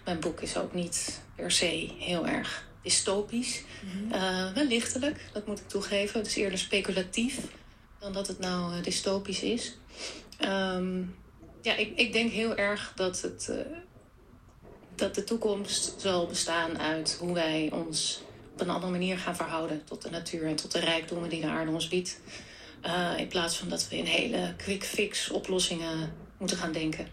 Een tijdje terug werd ik geïnterviewd voor de radio.
R-interview-thema.m4a